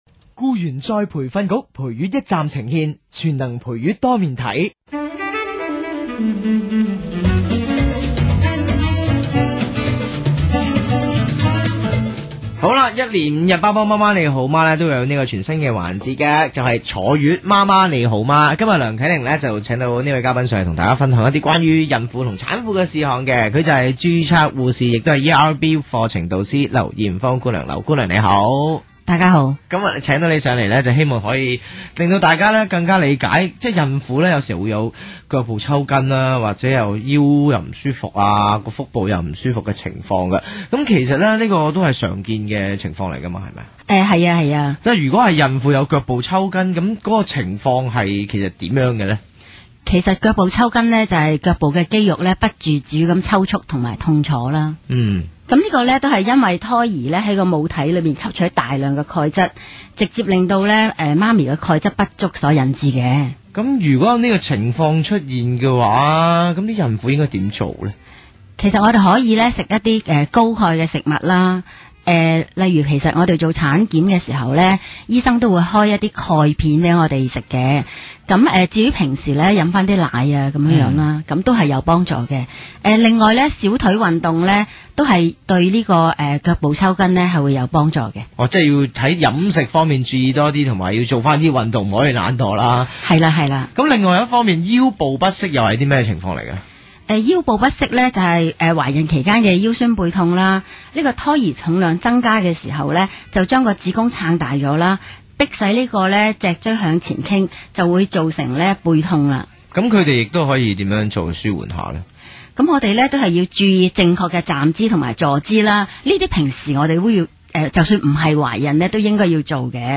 雇员再培训局「陪月一站」呈献 – 「全能陪月多面睇」于新城知讯台节目播出